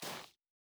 Bare Step Snow Medium C.wav